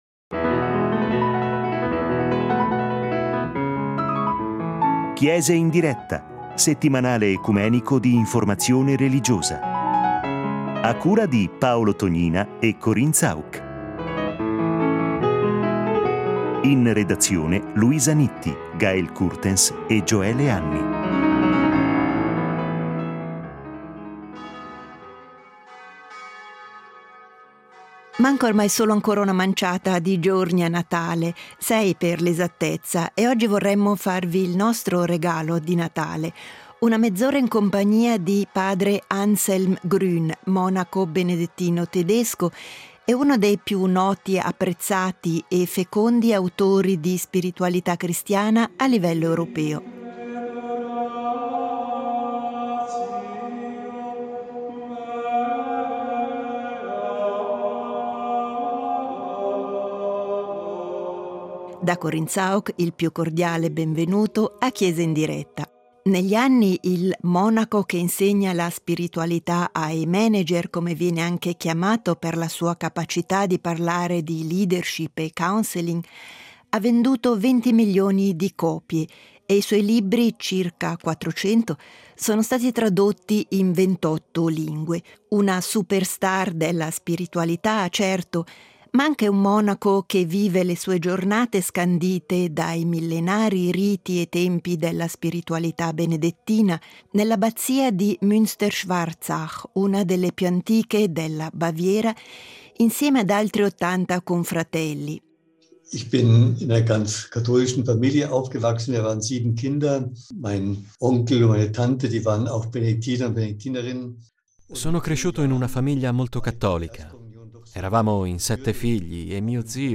Intervista ad Anselm Grün